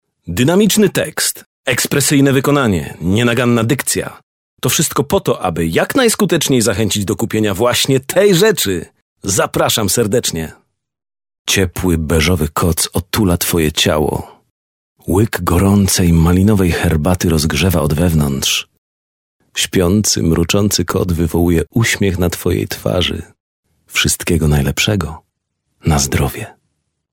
Male 30-50 lat
Impeccable diction and excellent interpretive skills of an actor.
Nagranie lektorskie